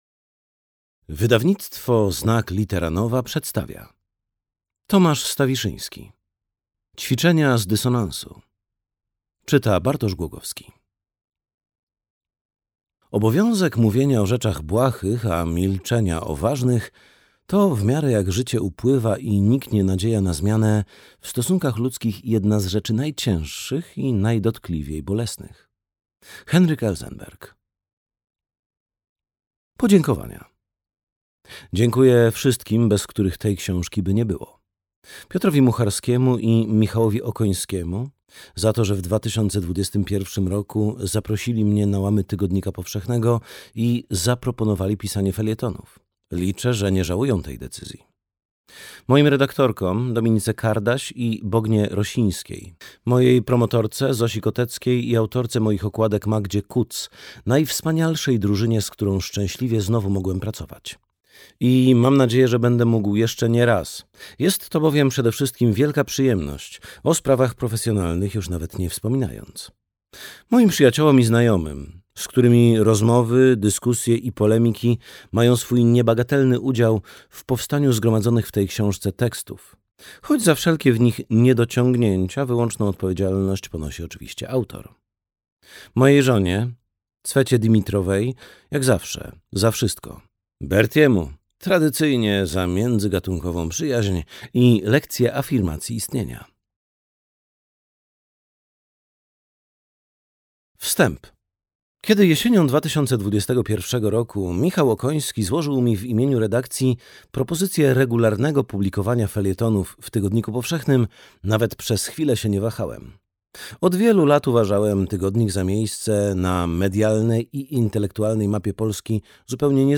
Ćwiczenia z dysonansu - Tomasz Stawiszyński - audiobook + książka